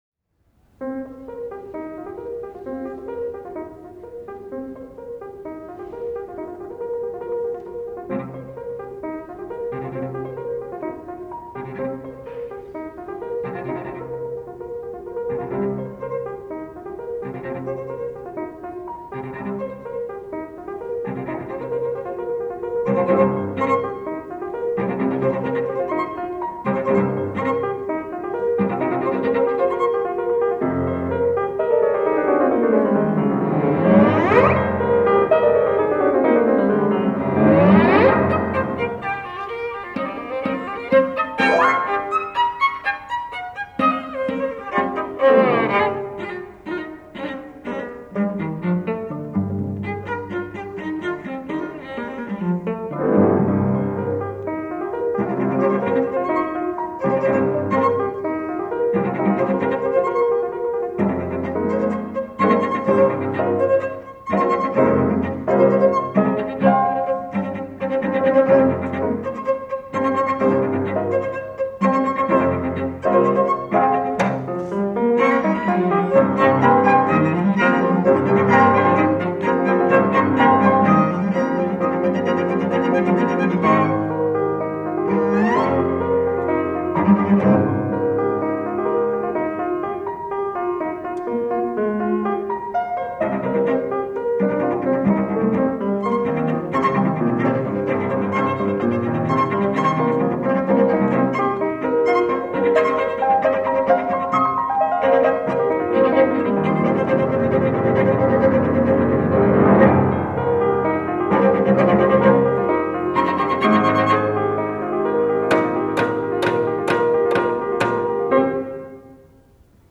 for Piano Trio (or Clarinet, Cello, and Piano) (1990)